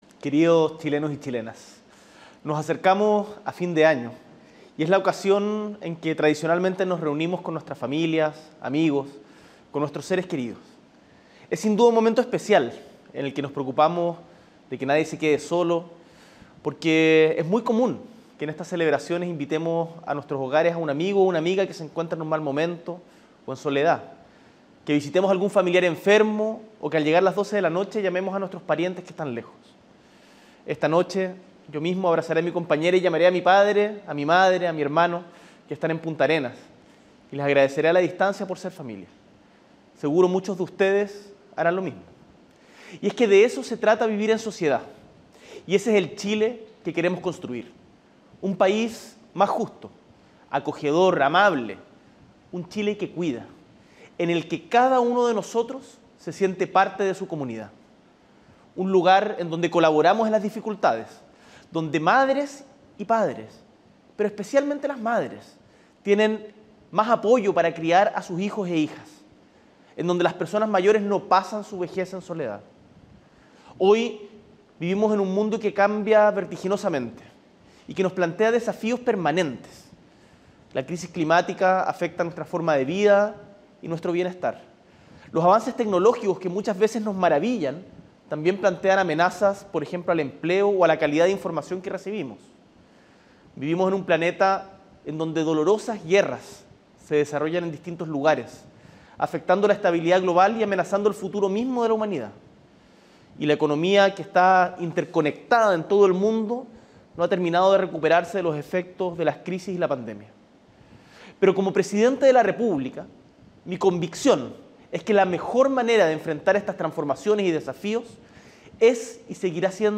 Este martes 31 de diciembre, desde el Palacio de La Moneda, el Presidente de la República, Gabriel Boric Font, se dirigió al país en cadena nacional para entregar un mensaje de fin de año.